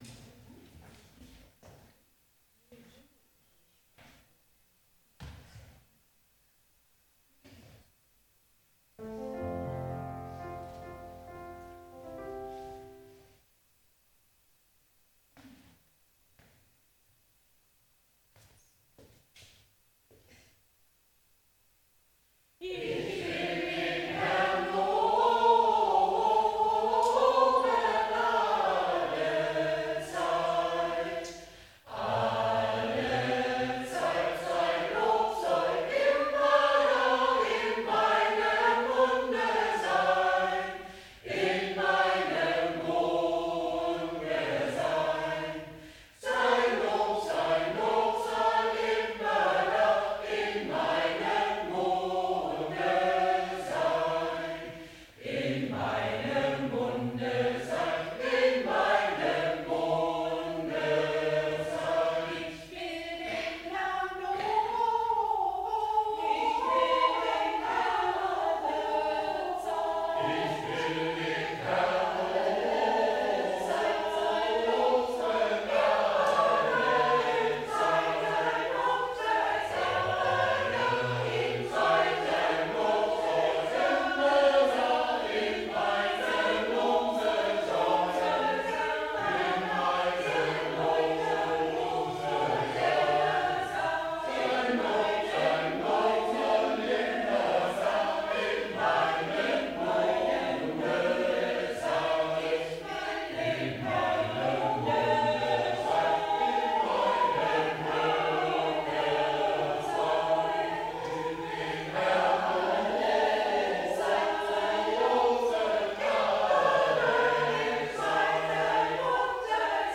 Gottesdienst - 18.05.2025 ~ Peter und Paul Gottesdienst-Podcast Podcast
Gottesdienst mit Kirchenchor in der Kirche